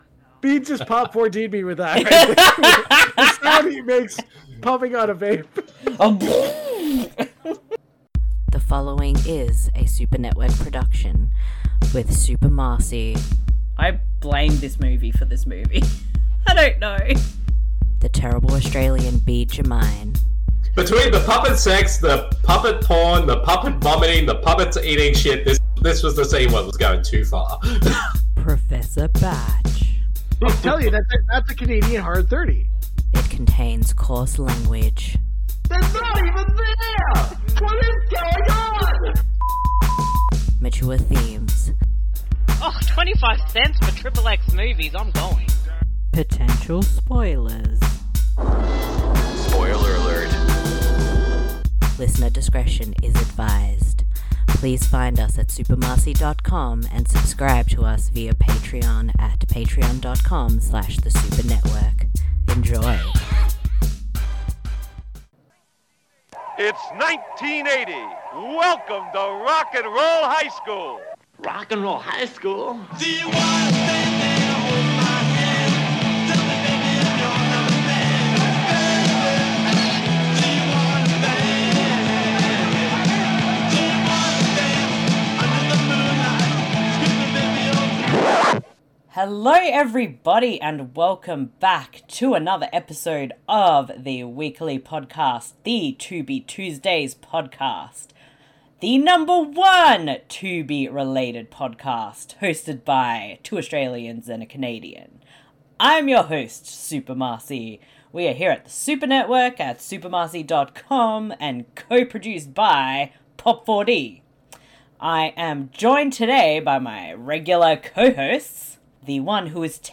DISCLAIMER: This audio commentary isn’t meant to be taken seriously, it is just a humourous look at a film.